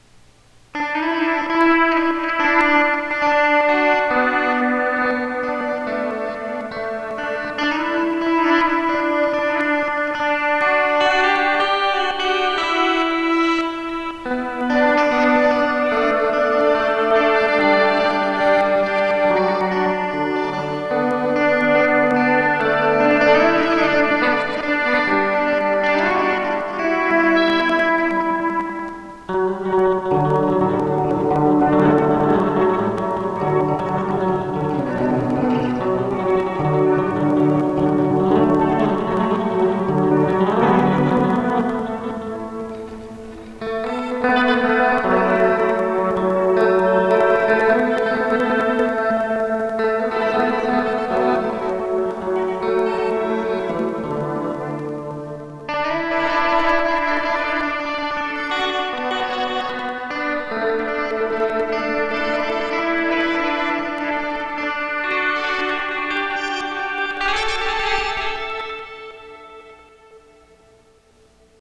Here it is in a musical context, with a similar buildup of the active FX tracks…